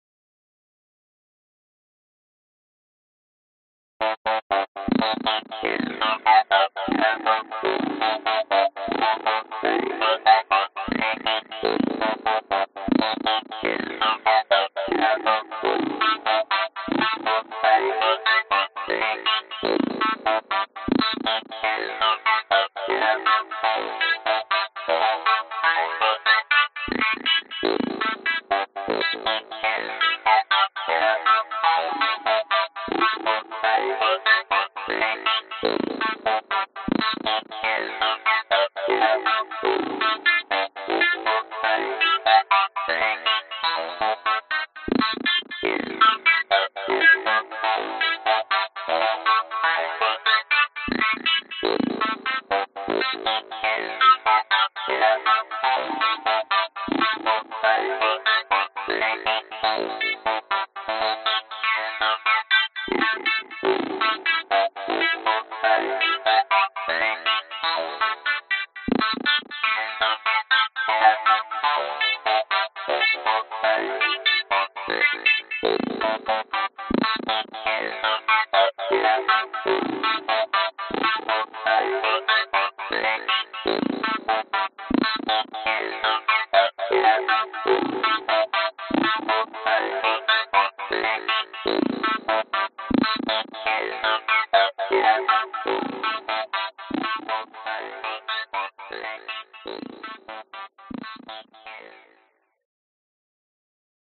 Tag: 牙医 电子 实验性 垫子 合成器 怪异